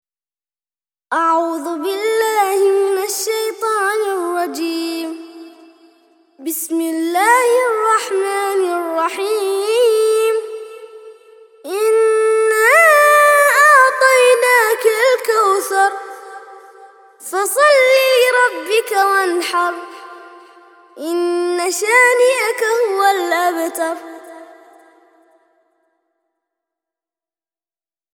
108- سورة الكوثر - ترتيل سورة الكوثر للأطفال لحفظ الملف في مجلد خاص اضغط بالزر الأيمن هنا ثم اختر (حفظ الهدف باسم - Save Target As) واختر المكان المناسب